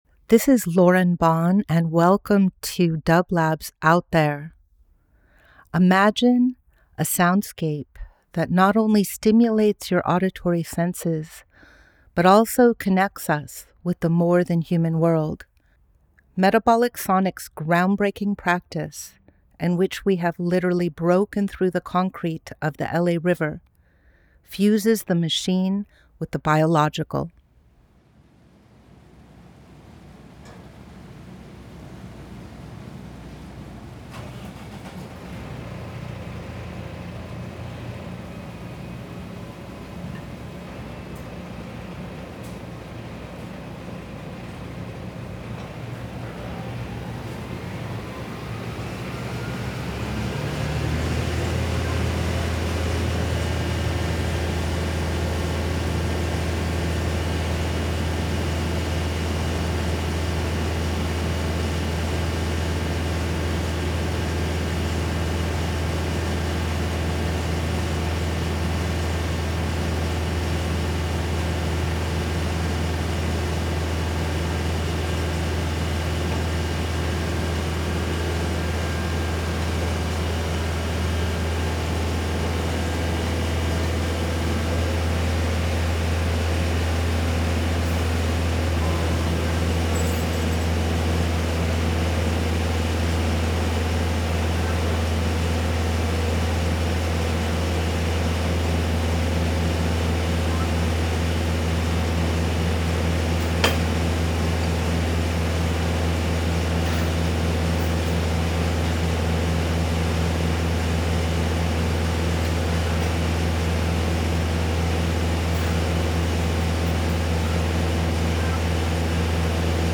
Each week we present a long-form field recording that will transport you through the power of sound.
This week we feature the sounds of the jack and bore team finishing up the bore-hole under the railroad tracks and placing the vitreous clay pipes that will carry water to the mother well at Metabolic Studio.